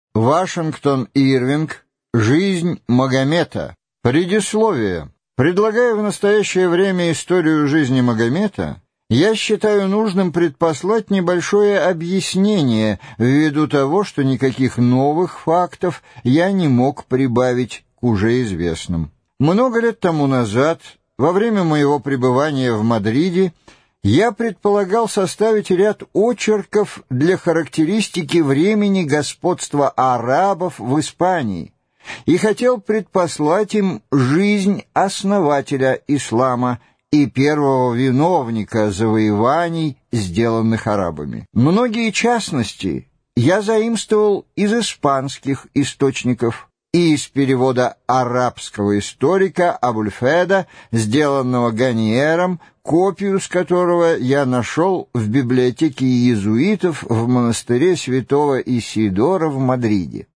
Аудиокнига Жизнь Магомета | Библиотека аудиокниг